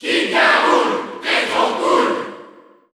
Category: King K. Rool (SSBU) Category: Crowd cheers (SSBU) You cannot overwrite this file.
King_K._Rool_Cheer_French_PAL_SSBU.ogg